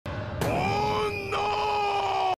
Play, download and share O NO original sound button!!!!
jojos-bizarre-adventure-joseph-joestar-oh-no.mp3